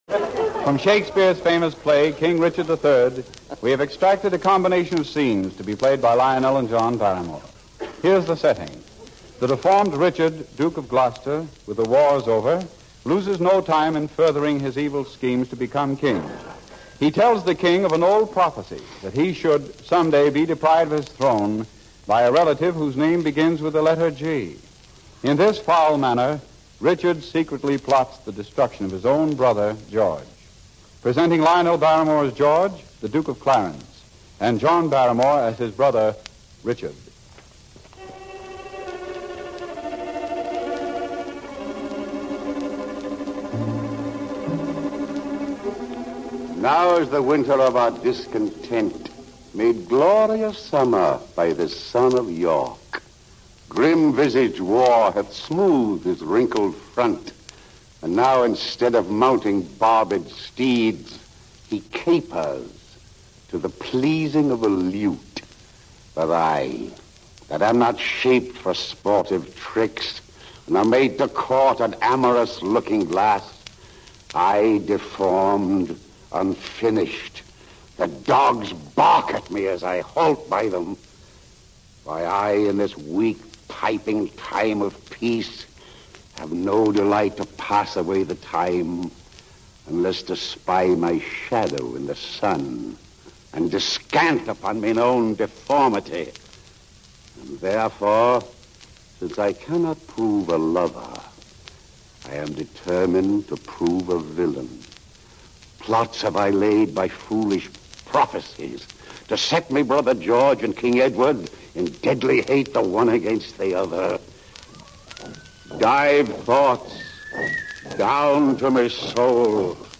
The Sealtest Show was a slapstick comedy series where the stars and guests were satirized. But just to let listeners know it was all in fun, John and Lionel took time out to perform a scene from Shakespeare’s RICHARD III. Here Rudy Vallee introduces the scene on the May 1, 1941 broadcast: